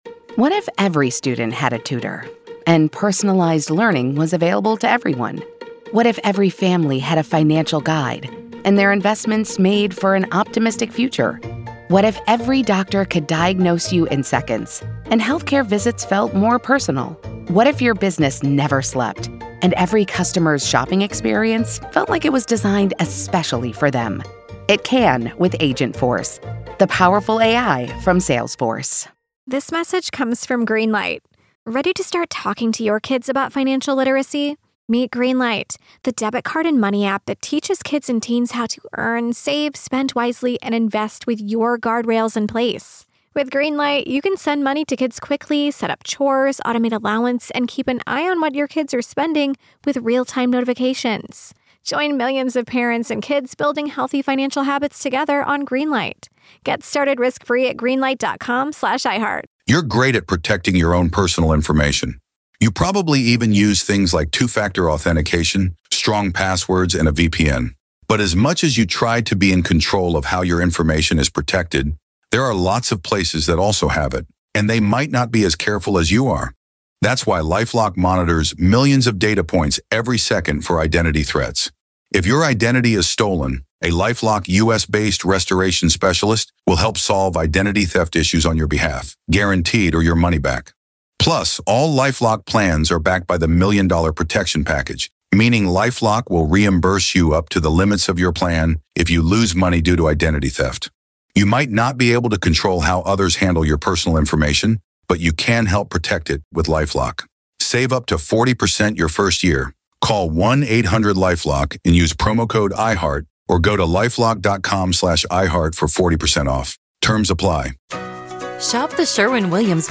In this searing follow-up interview